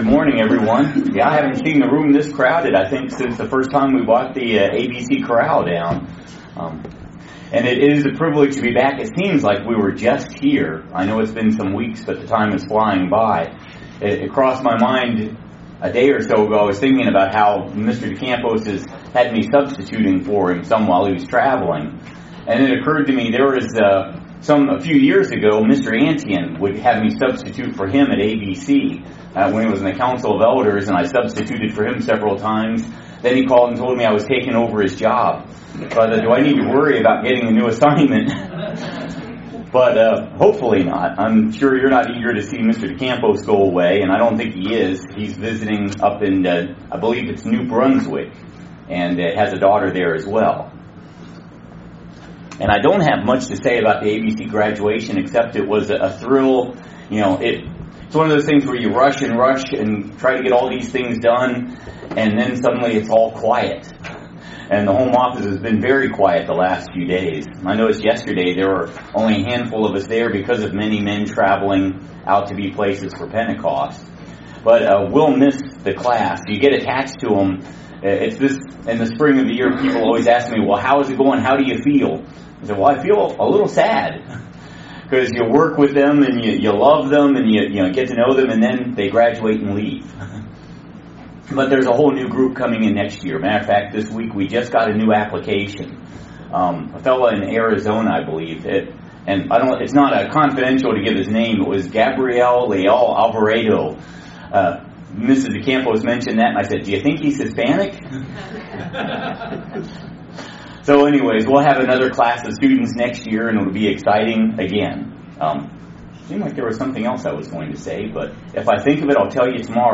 Excellent Sermon on the Church being the Bride of Christ. Join us for this engaging message about what it means to be the Bride of Christ.